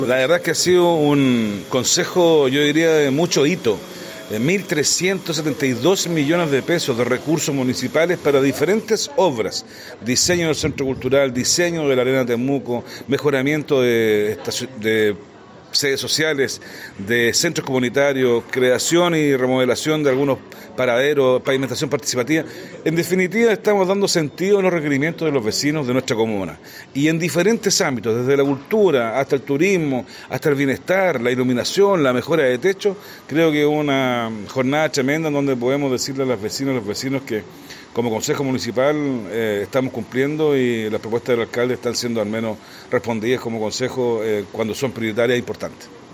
Alejandro-Bizama-concejal-temuco.mp3